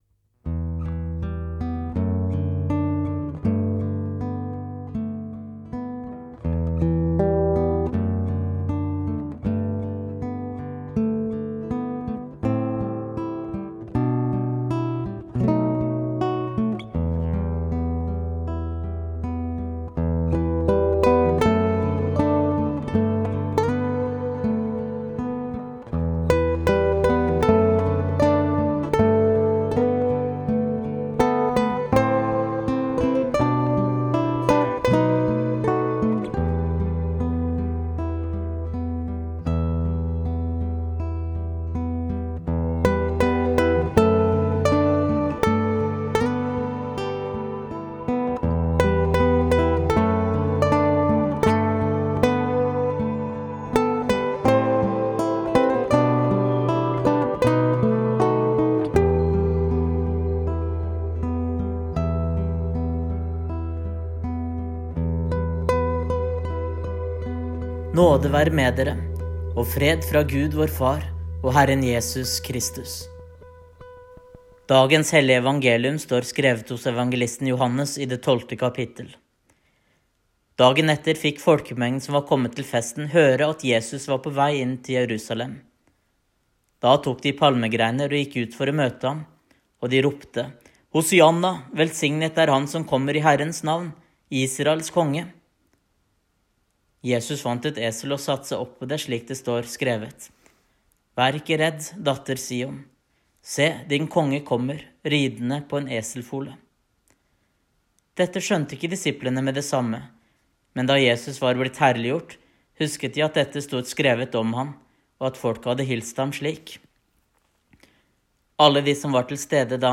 Her kan du høre søndagens minigudstjeneste på podcast.
Palmesøndag minigudstjeneste
podcast ep07 - palmesøndag minigudstjeneste.mp3